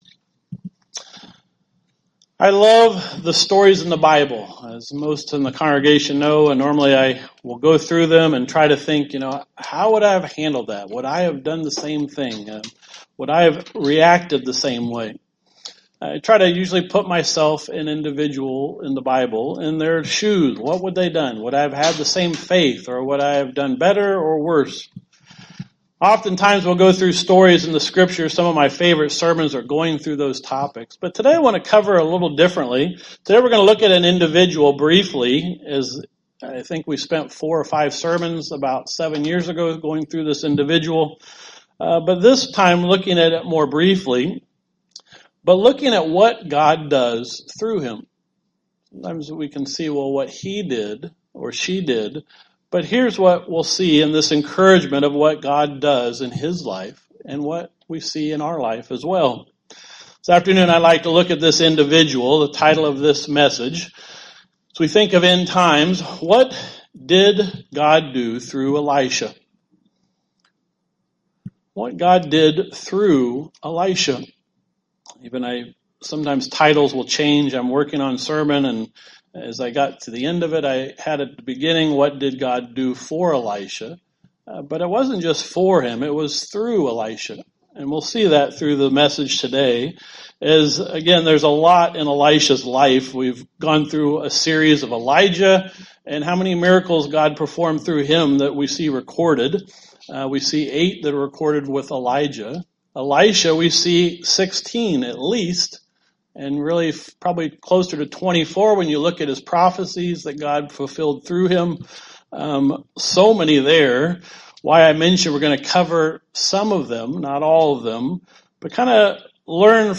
Given in Elkhart, IN Northwest Indiana